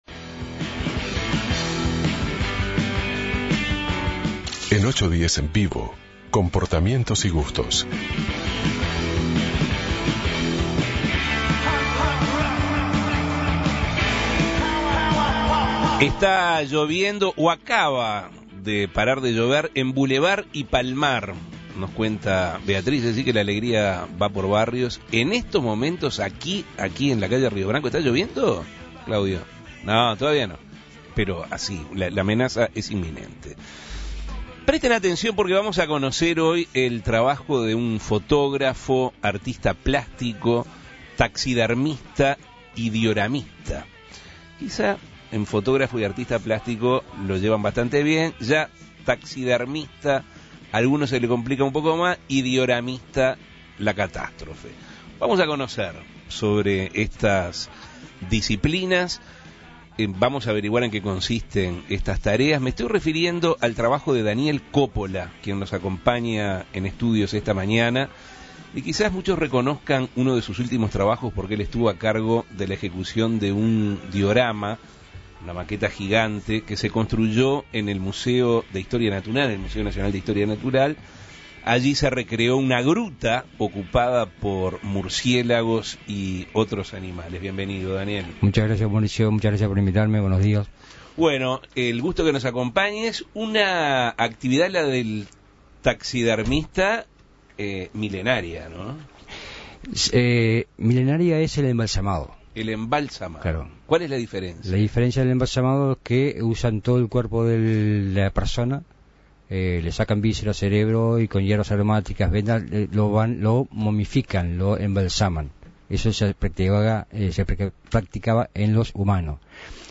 Taxidermia y Diorama Entrevista